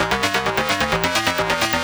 CS_FMArp C_130-A.wav